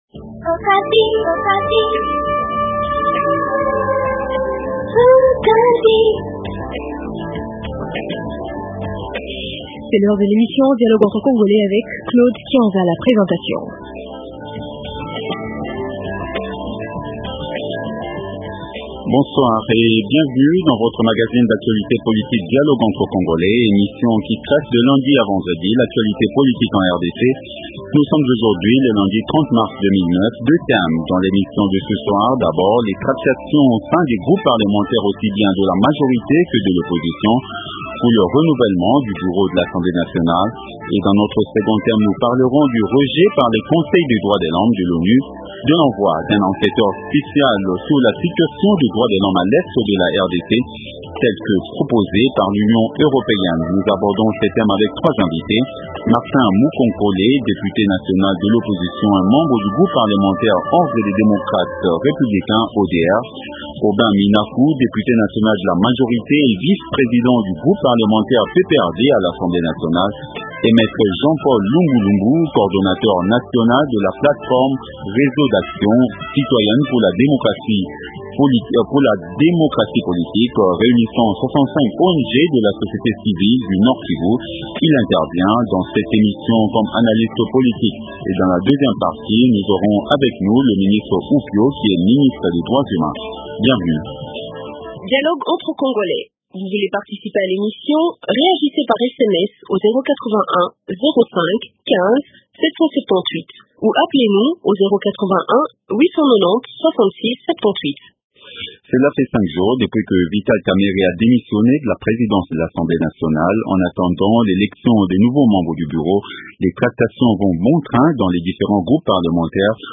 Deux thèmes dans le débat de ce soir, à savoir:Les tractationspour le renouvellement du bureau de l’Assemblée nationale et Le refus par le conseil des droits de l’homme de l’Onu de l’envoie d’un enquêteur spécial sur la situation des droits de l’homme à